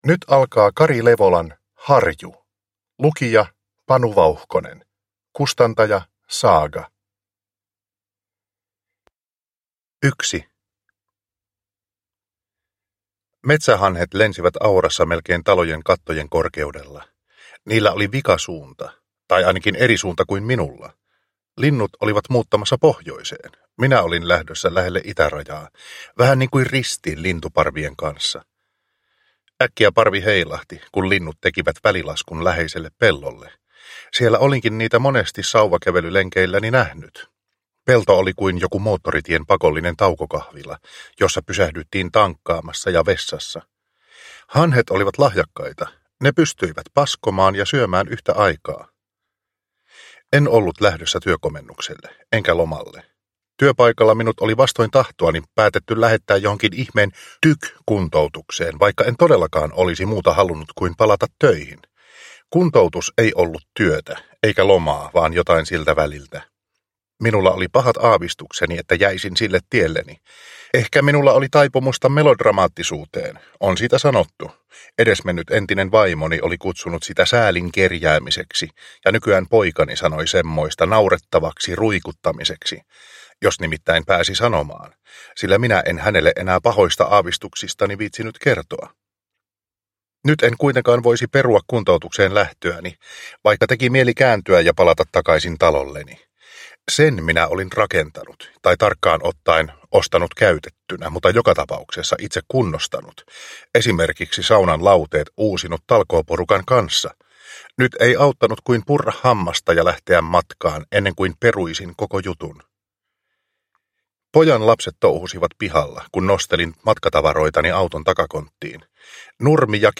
Harju / Ljudbok